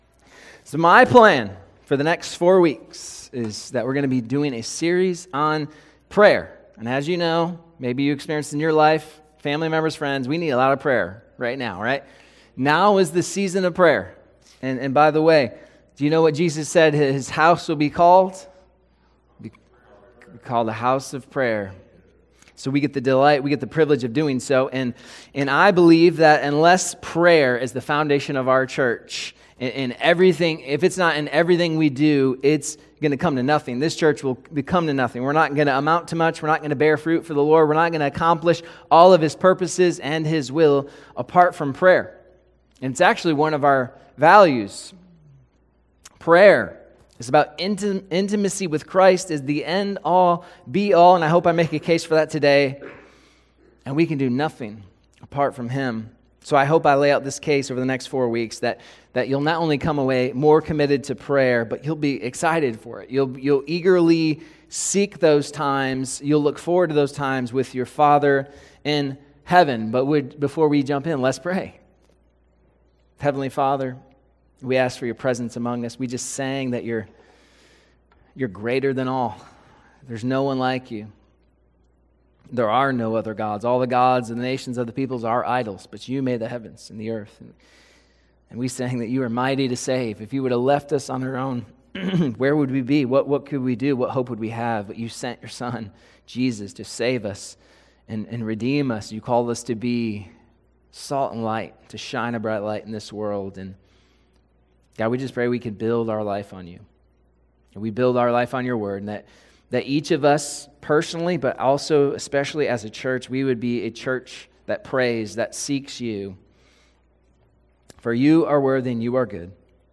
Sermons | Damascus Church